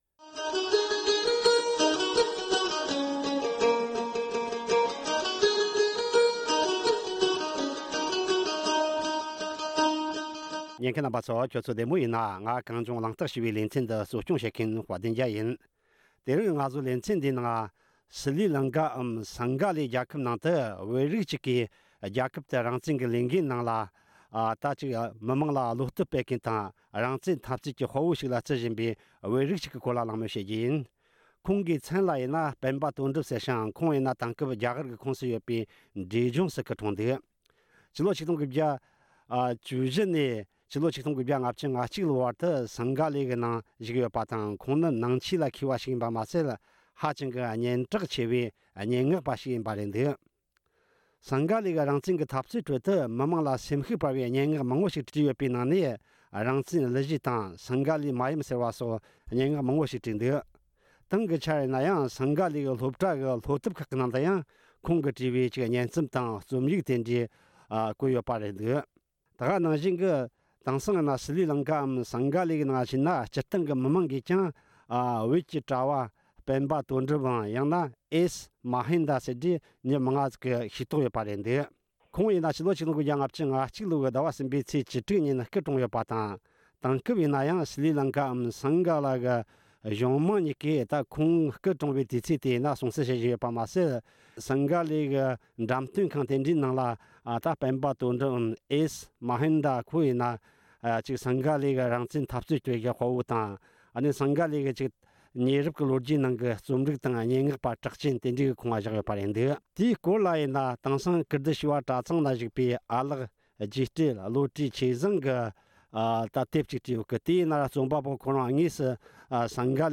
གླེང་མོལ་ཞུས་ཡོད།